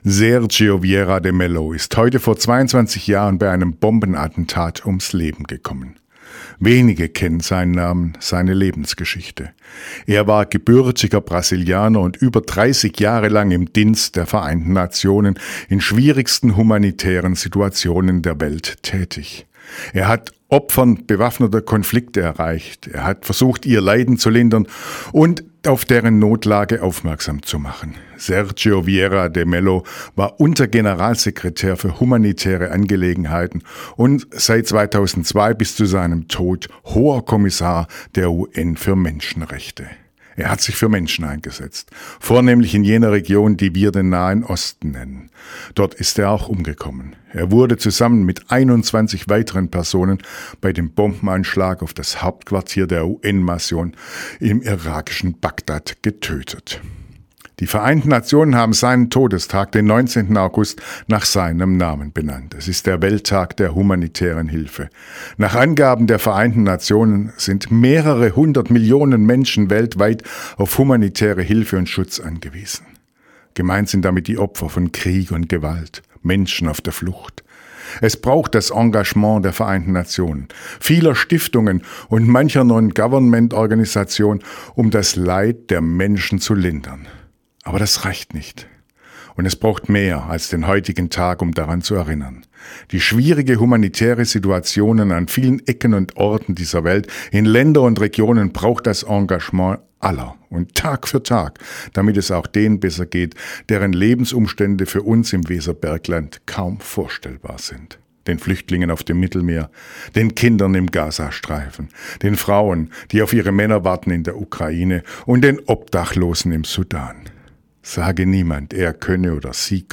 Radioandacht vom 19. August